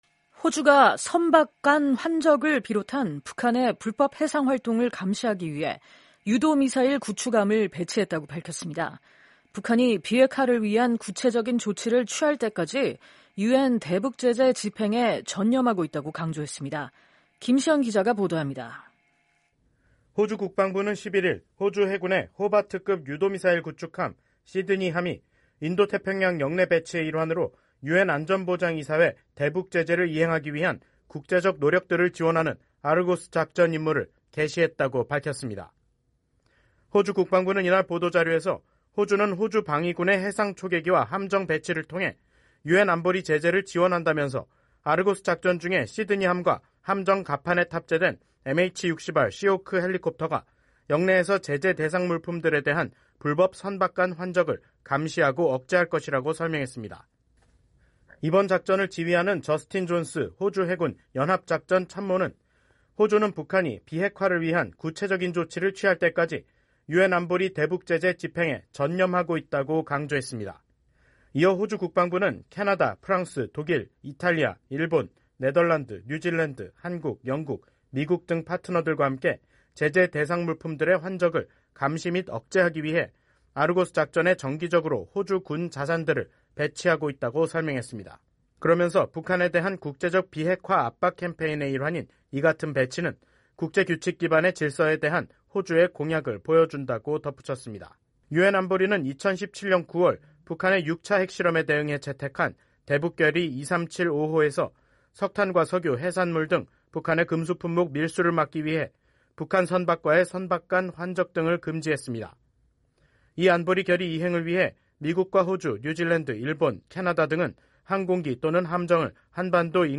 보도입니다.